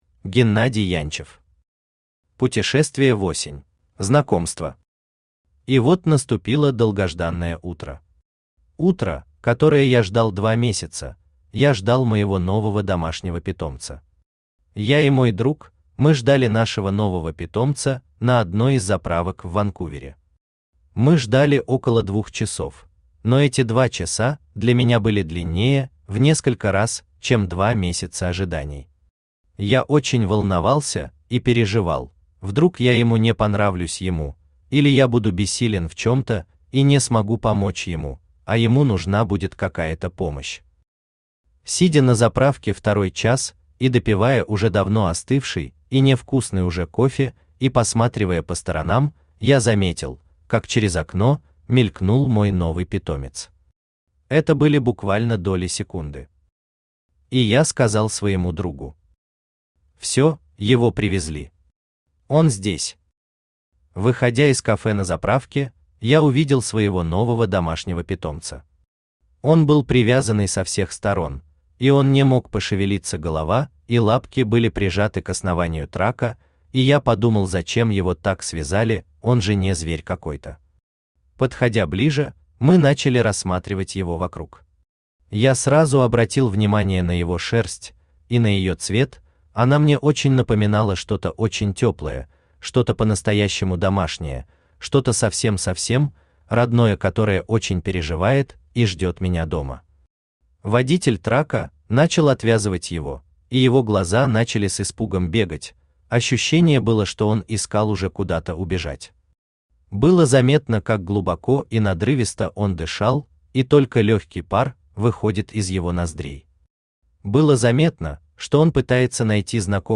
Аудиокнига Путешествие в осень | Библиотека аудиокниг
Aудиокнига Путешествие в осень Автор Геннадий Янчев Читает аудиокнигу Авточтец ЛитРес.